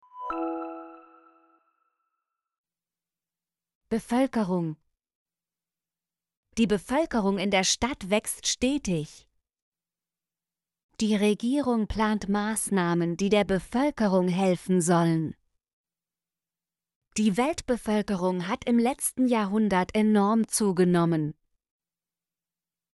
bevölkerung - Example Sentences & Pronunciation, German Frequency List